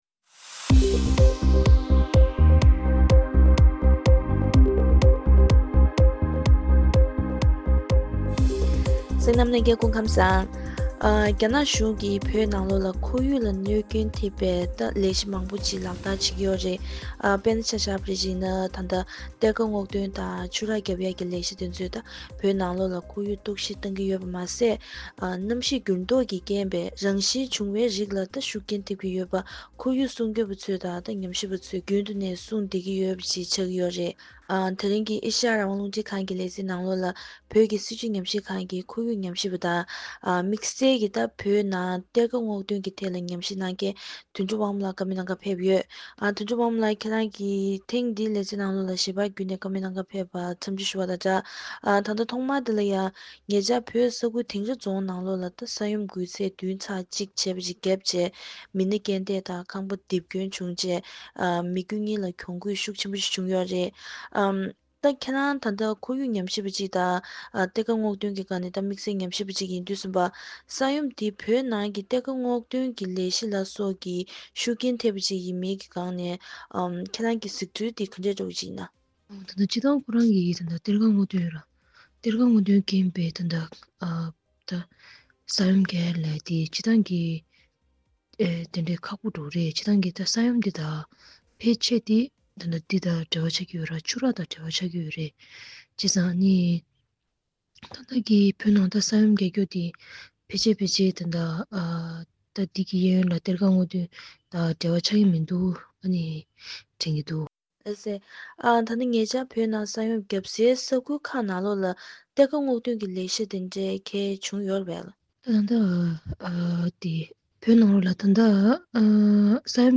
གཏེར་ཁ་སྔོག་འདོན་གྱིས་རྐྱེན་པས་ཁོར་ཡུག་གཏོར་བཤིག་གི་སྐོར་ལ་བོད་ཀྱི་སྲིད་ཇུས་ཉམས་ཞིབ་པ་ལ་བཀའ་འདྲི།